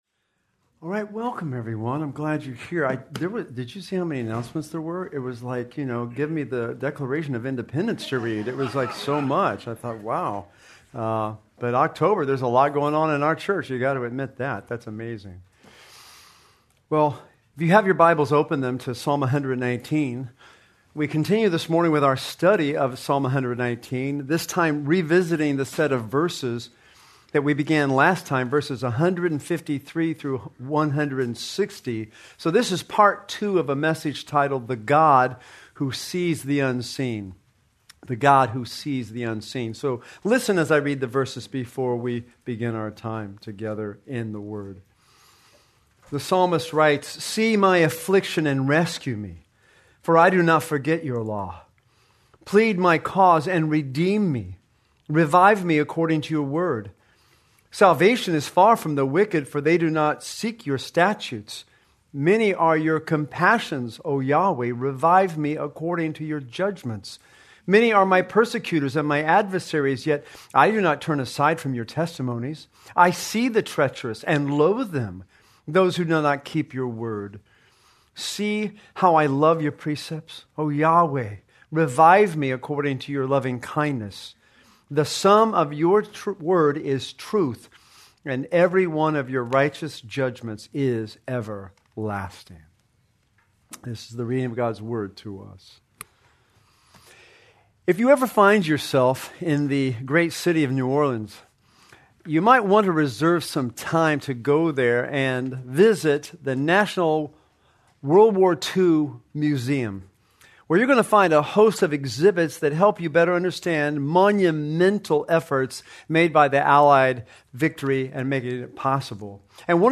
Due to technical difficulties, this sermon is incomplete.